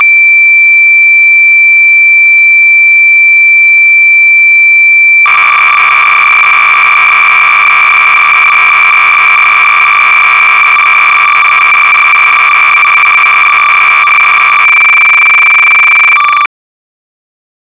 Click to enlarge Originally the FX-502P and associated calculators were designed to record and replay programs from tape. I have converted some program recordings to the familiar *.wav format.